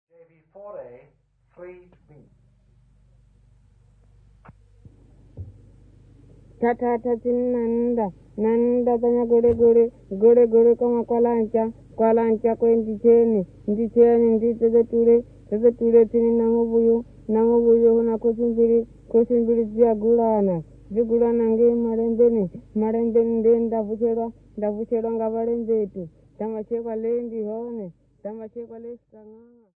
Folk Music
Field recordings
Africa South Africa Limpopo Province f-sa
sound recording-musical
Indigenous music